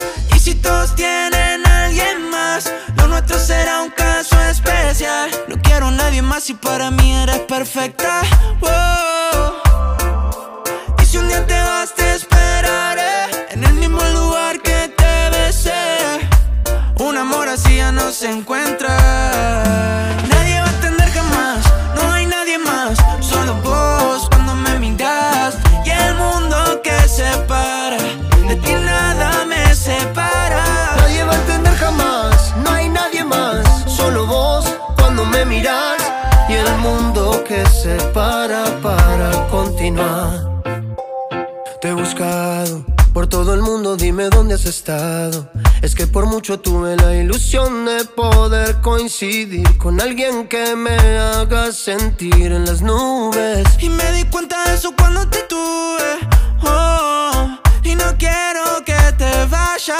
canción
tiene la voz de Yatra